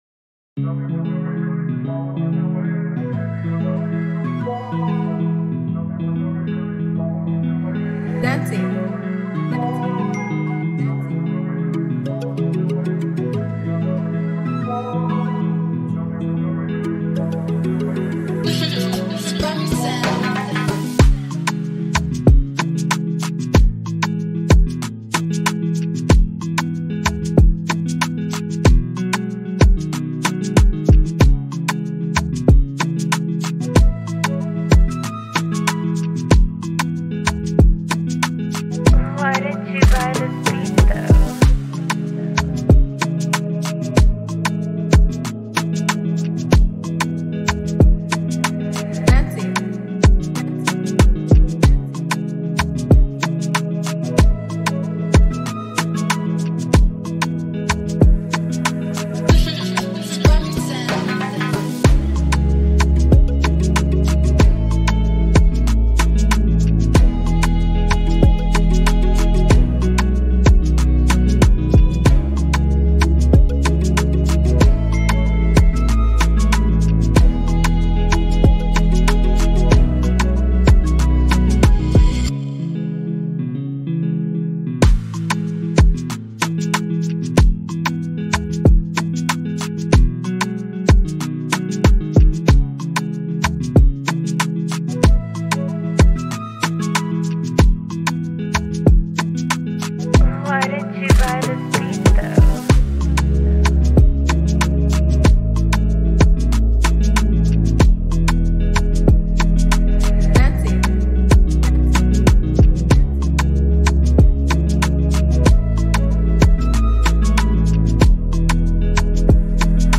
Here is a free sad and emotional afrobeat instrumental